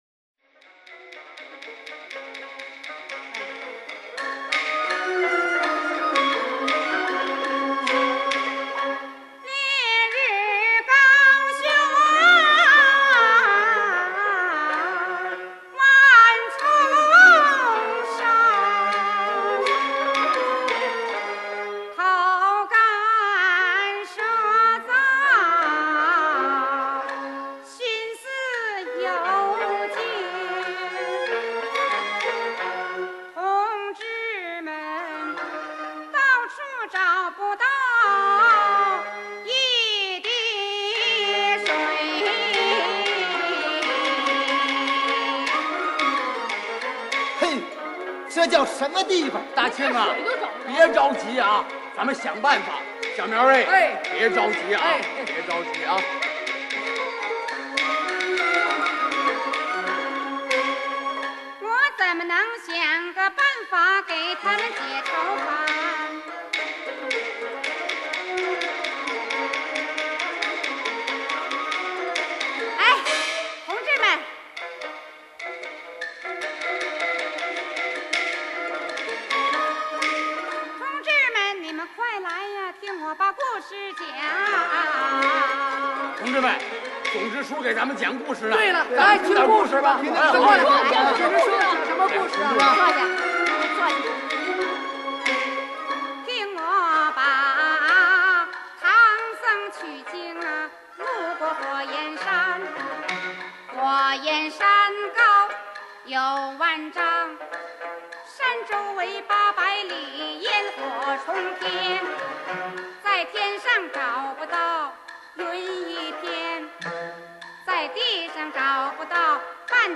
1963年密纹唱片版录音